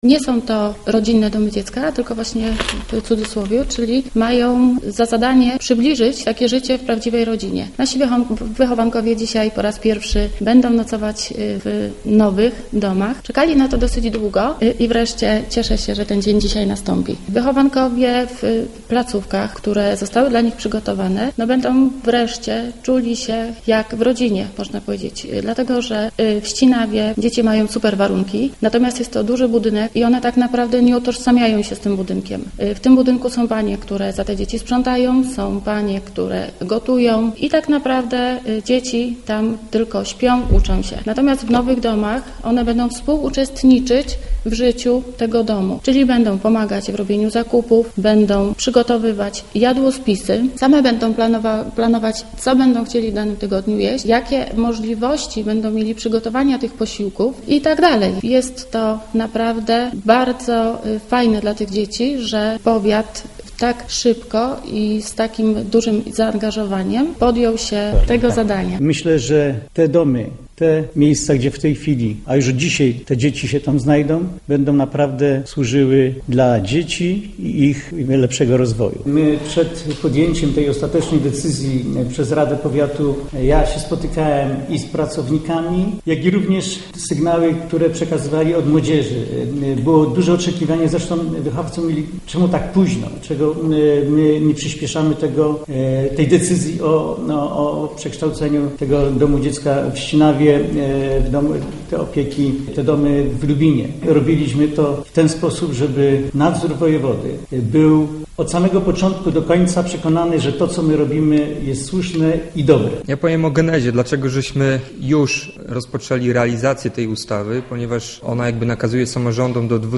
starosta Adam Myrda i członek zarządu powiatu Damian Stawikowski